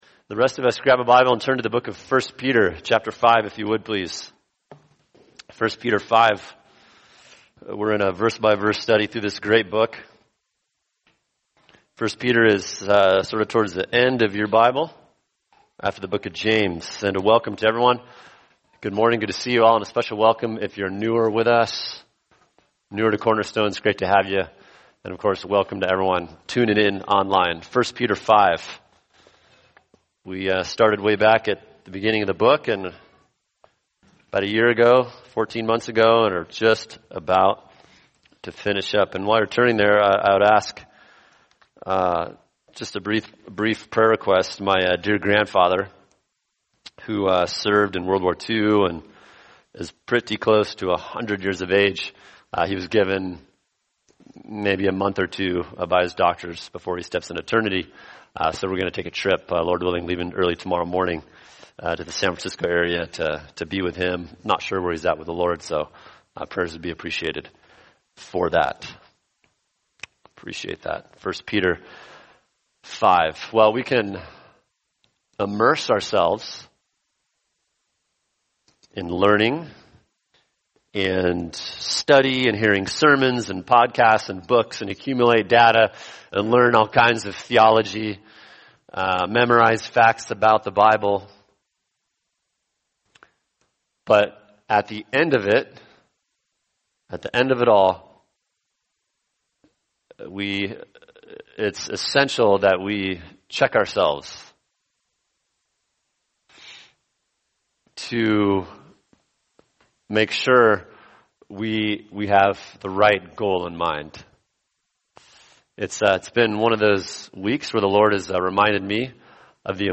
[sermon] 1 Peter 5:1-4 The Kind of Shepherding God Wants For Us – Part 3 | Cornerstone Church - Jackson Hole